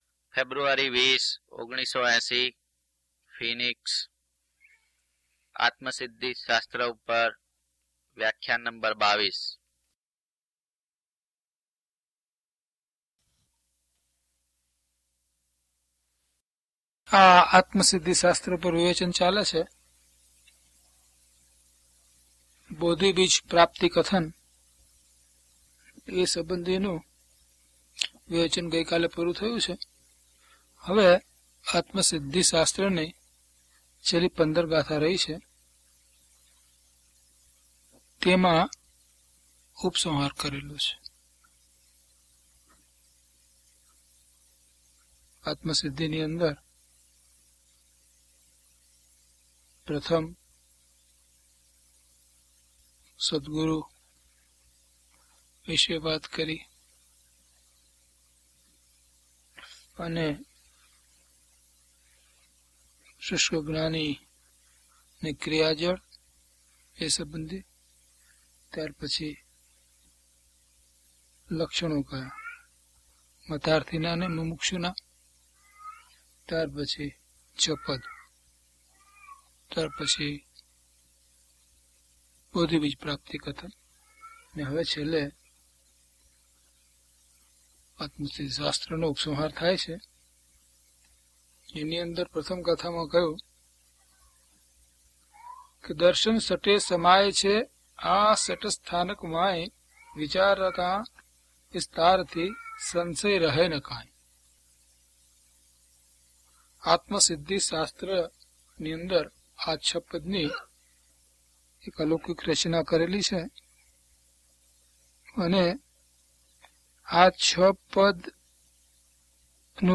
DHP031 Atmasiddhi Vivechan 22 - Pravachan.mp3